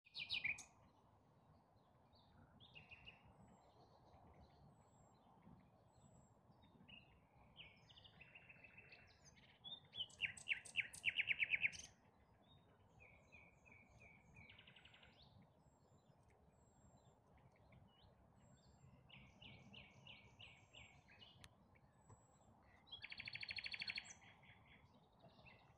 Die Nachtigall-Gesänge nahm ich jedoch in diesem Jahr auf dem Tempelhofer Feld auf.
Nachtigall-Tempelhofer-Feld.mp3